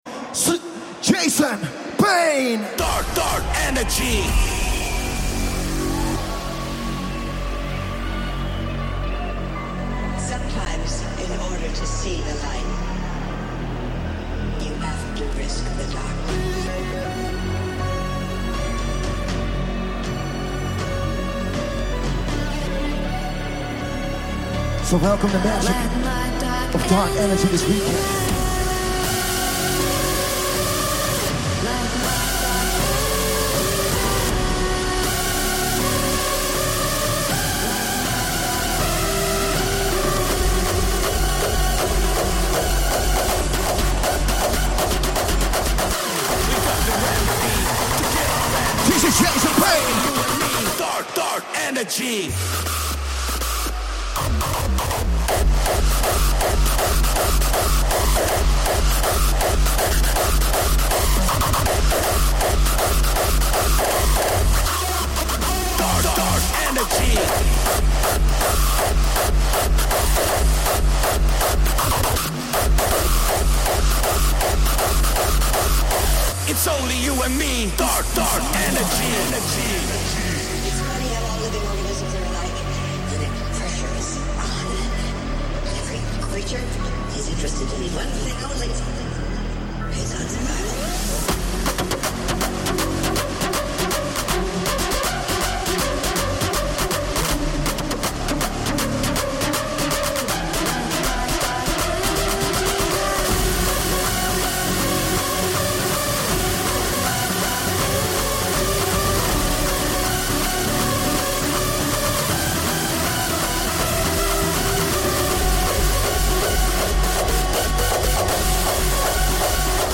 liveset/dj mix